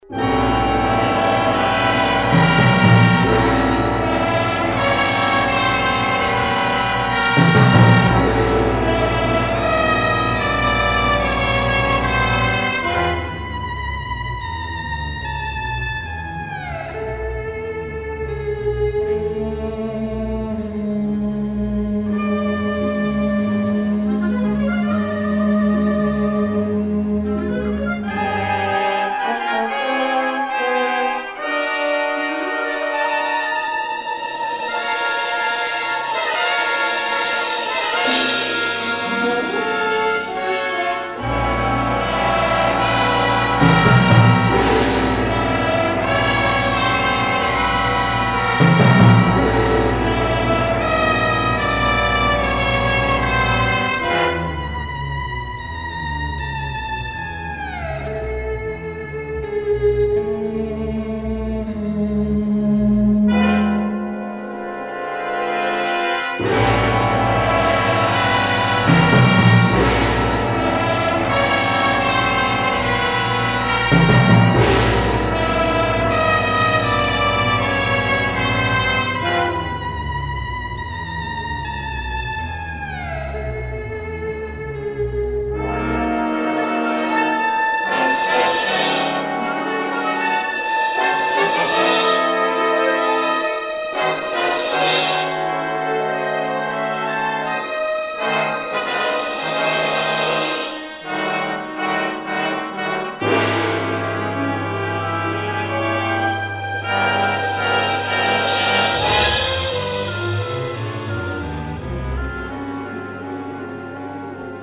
Well, make that Shakespeare with an electric violin.
electric violin